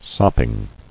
[sop·ping]